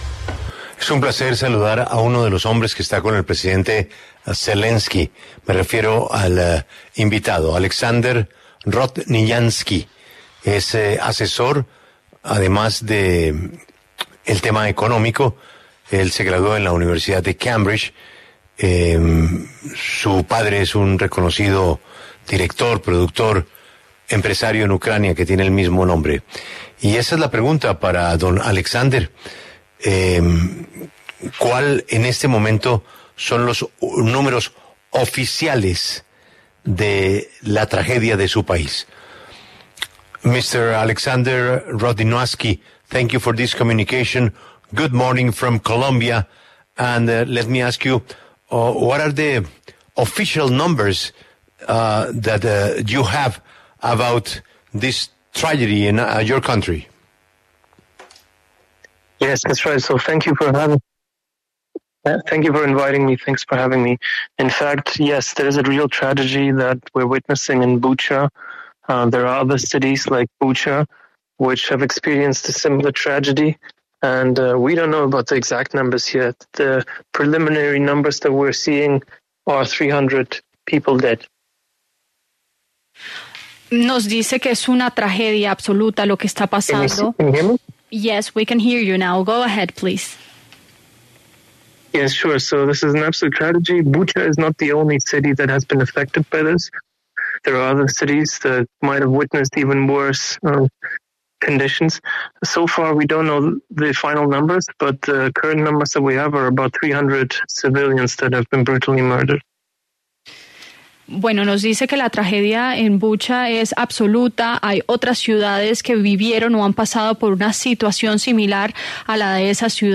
Alexander Rodnyansky, asesor del presidente de Ucrania, Volodymir Zelenski, habló en La W sobre el balance de la guerra con Rusia.
En el encabezado escuche la entrevista completa con Alexander Rodnyansky, uno de los asesores del presidente de Ucrania, Volodymir Zelenski.